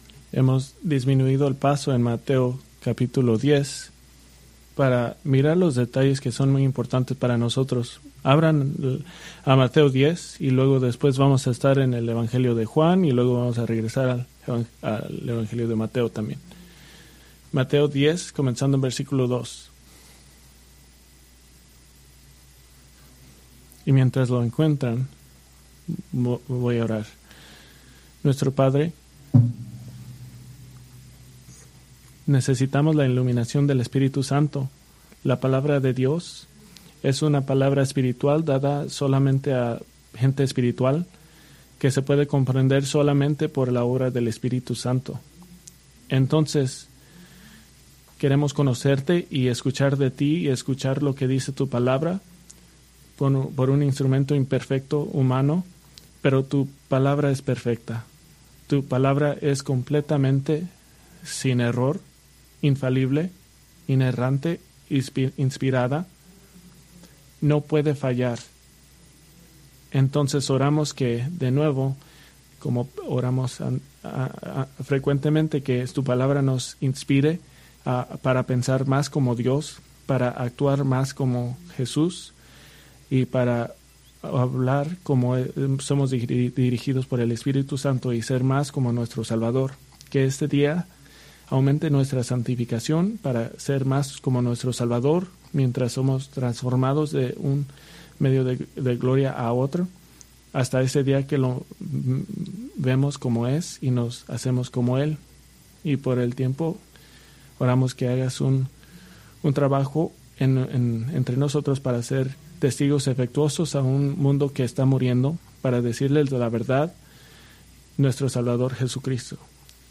Preached March 2, 2025 from Mateo 10:3